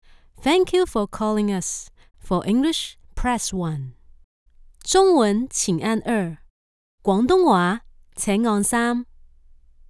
Female
Natural, engaging, authentic, with strong professional delivery
Radio Commercials
Radio Ads In Hk